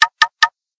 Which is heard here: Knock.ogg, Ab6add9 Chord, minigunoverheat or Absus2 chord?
Knock.ogg